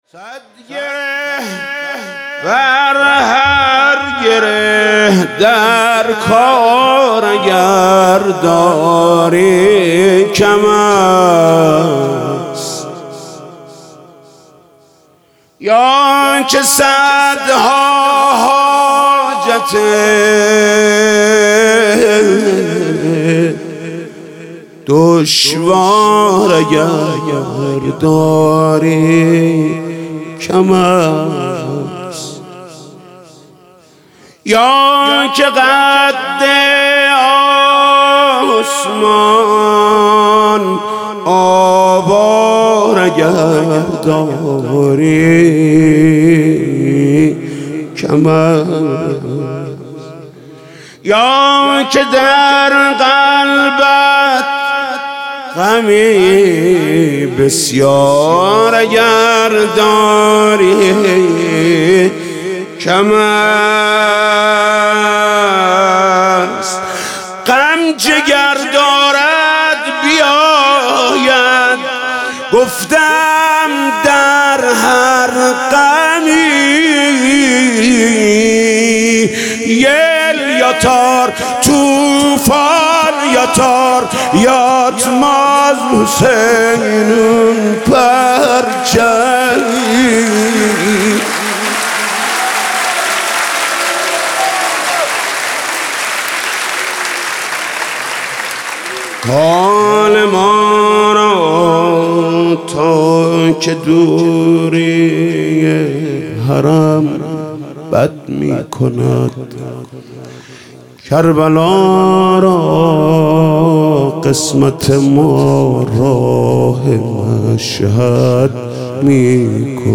مدح: صد گره بر هر گره در کار اگر داری کم است